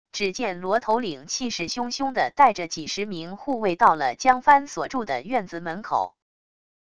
只见罗头领气势汹汹地带着几十名护卫到了江帆所住的院子门口wav音频生成系统WAV Audio Player